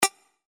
システム電子音(シンプル-短) 100 クリック 選択移動 01
/ F｜システム電子音 / F-02 ｜システム2 さらにシンプルな電子音 カーソル移動などに